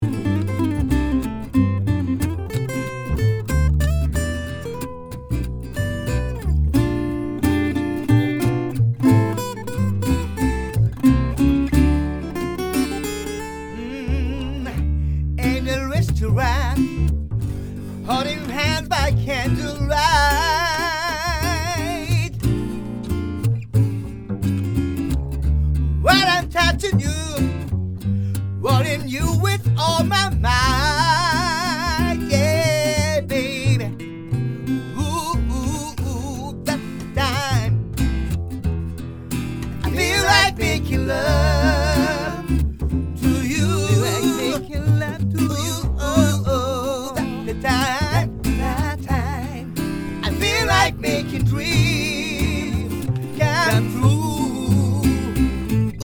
コーラスパートを録った。